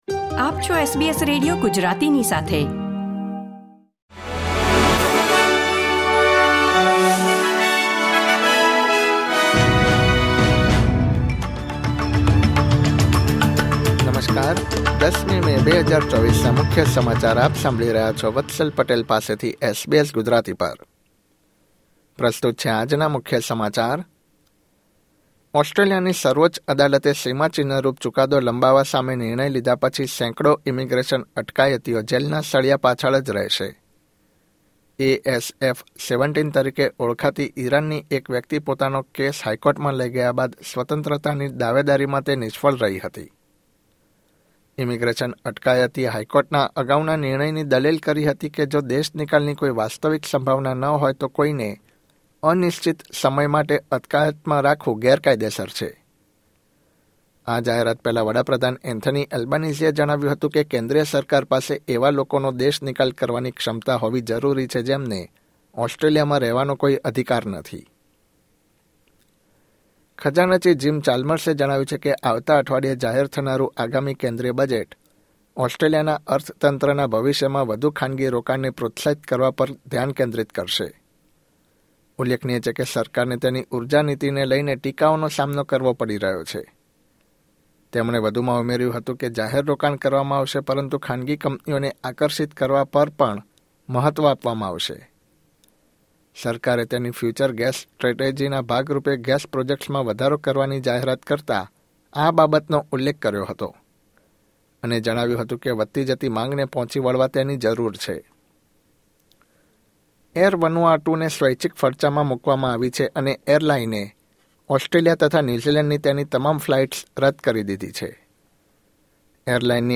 SBS Gujarati News Bulletin 10 May 2024